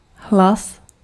Ääntäminen
France: IPA: /vwa/